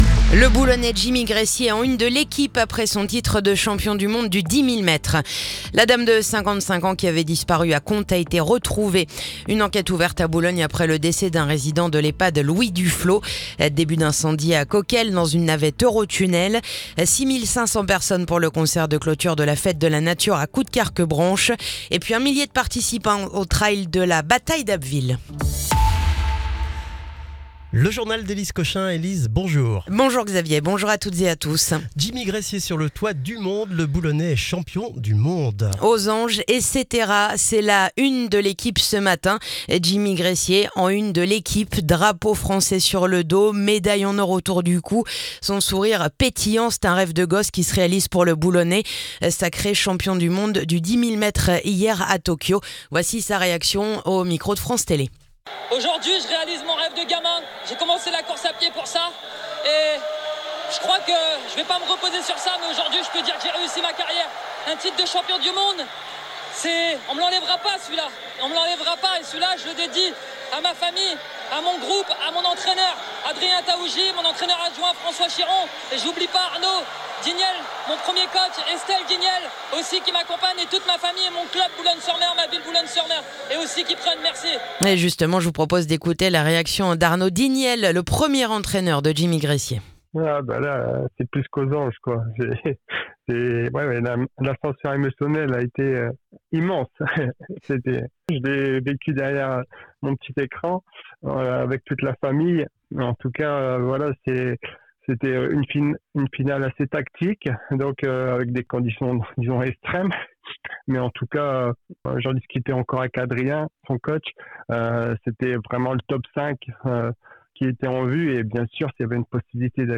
Le journal du lundi 15 septembre